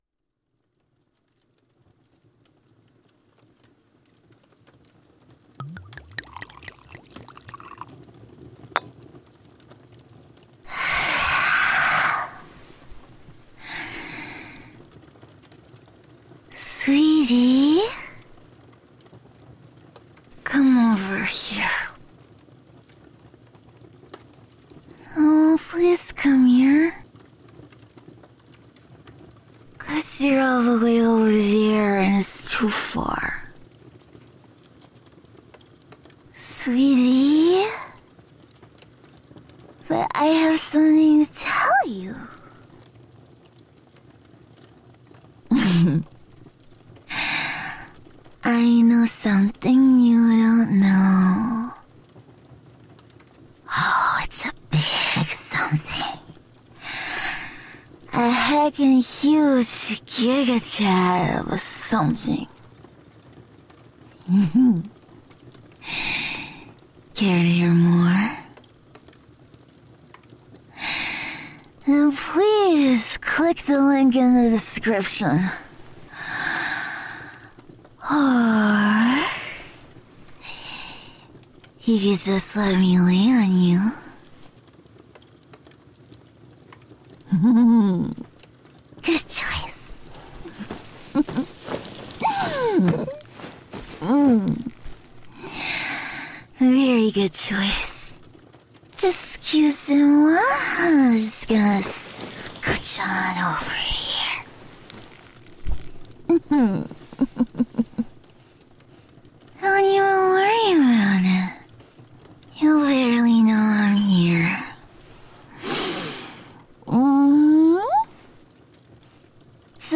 (F4A) (Strong Lamia Girlfriend Gets Tipsy & Showers You with Love)
(Adorable Rambling)